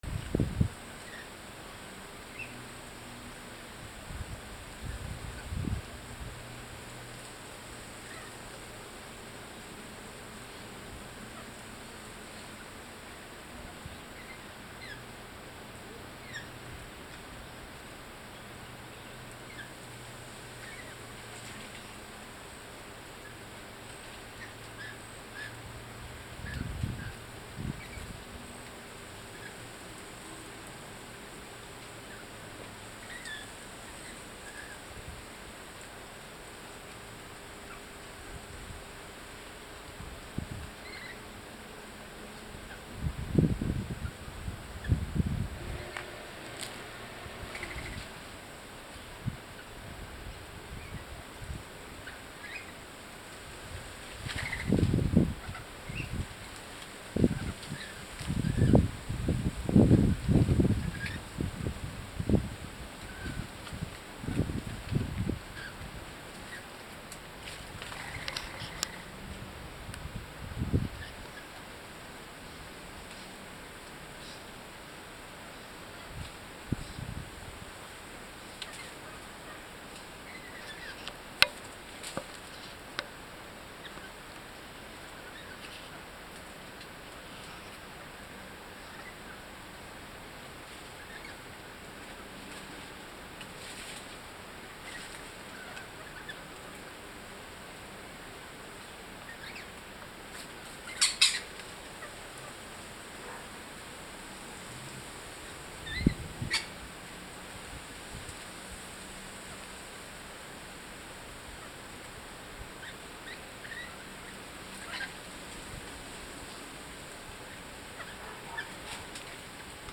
Rainbow Lorikeets in Guava Tree